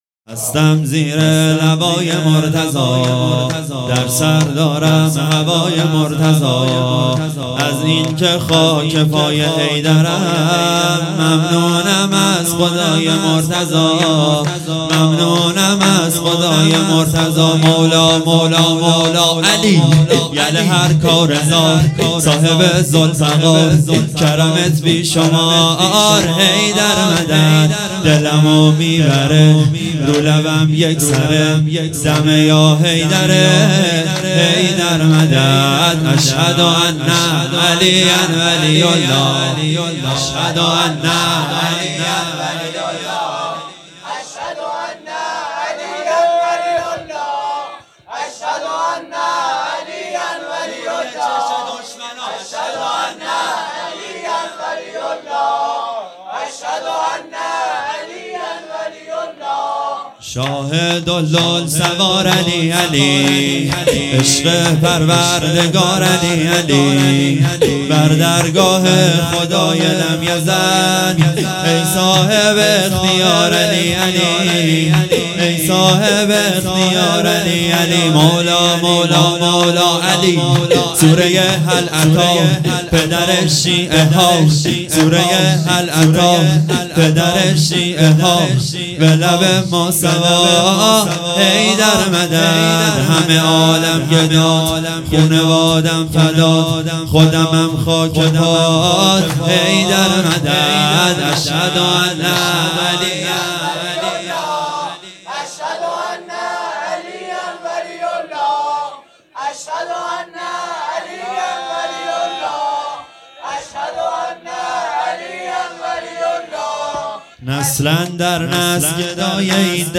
شور | هستم زیر لوای مرتضی
◾مراسم شهادت امیر المؤمنین امام علی(ع)◾
شب های قدر (ماه رمضان ۱۴۴۳)